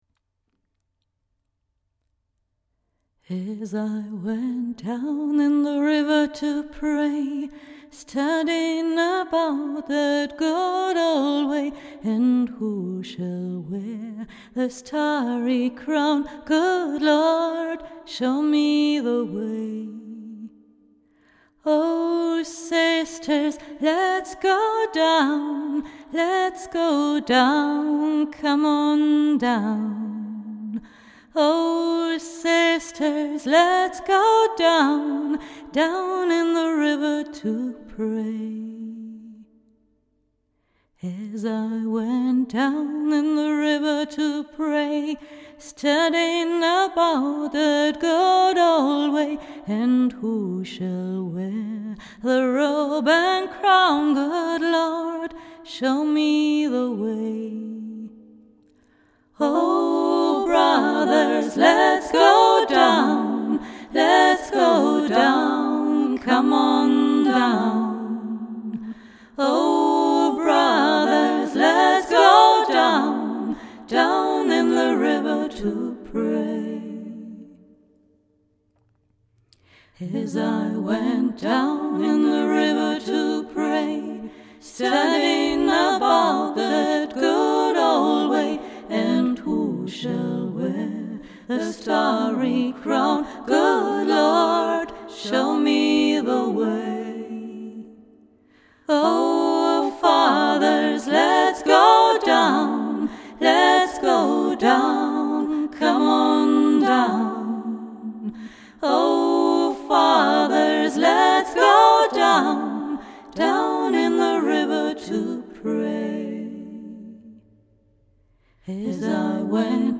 Gospel/Trad.